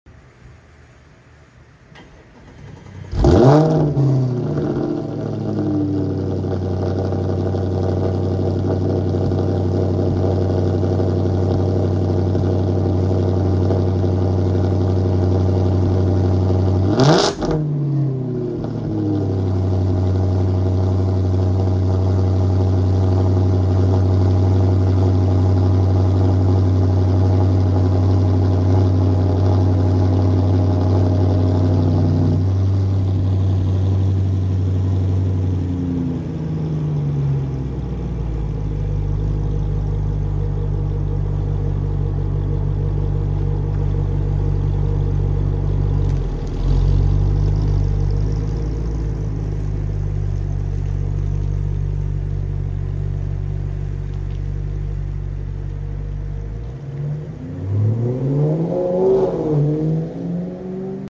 🔥 Audi RS5 B8.5 Cold sound effects free download
🔥 Audi RS5 B8.5 Cold Start – Armytrix Downpipes + AWE Track Exhaust = EARTHQUAKE MODE 🔥 This ain’t your average V8 startup… the 4.2L FSI roars to life with pure aggression thanks to Armytrix downpipes and the savage tone of the AWE Track exhaust! 💥💨 It’s raw, high-pitched, and straight-up violent — exactly how a German V8 should sound.